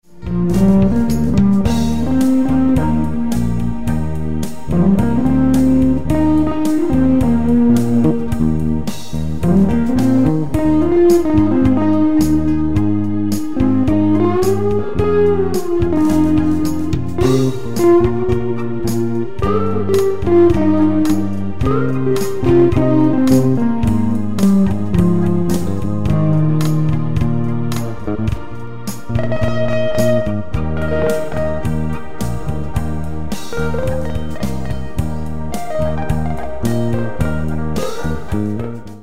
Sie sind zum träumen und entspannen gedacht.
Nur ein melodischer Gitarrensong, der sich steigert.